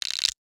NOTIFICATION_Subtle_05_mono.wav